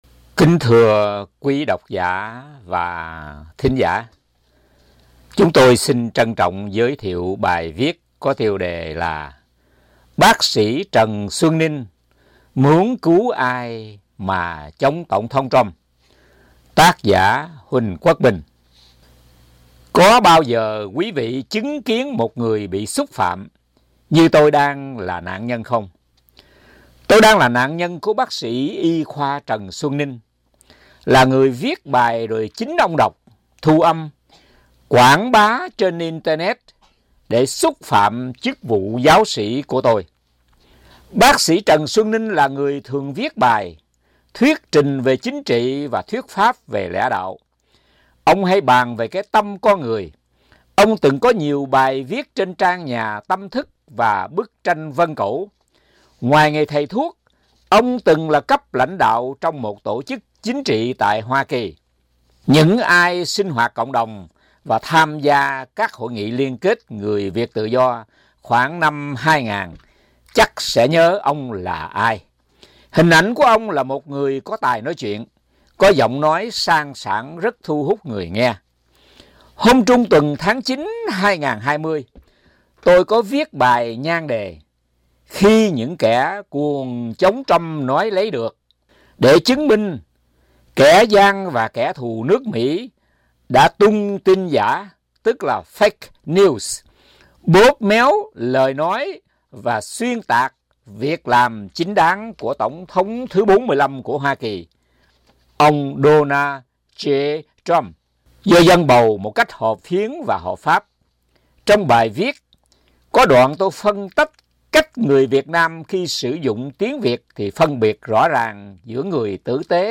Xin mời bấm link để nghe hoặc tải xuống máy, âm thanh bài viết qua giọng đọc của chính tác giả: